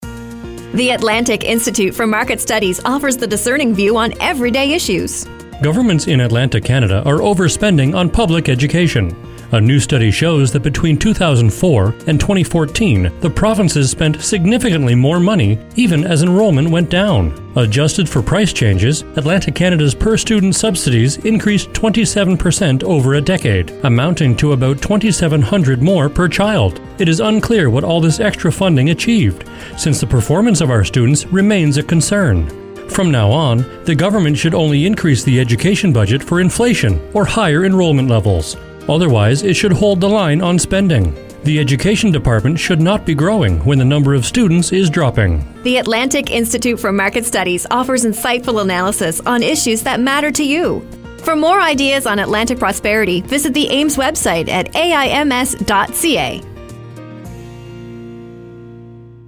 Radio: Education Spending Levels -